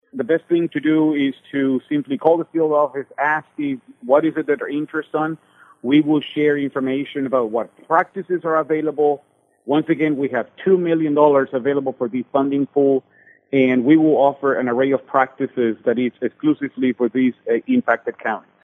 Florida State Conservationist, Juan Hernandez, explains what producers in those counties should do.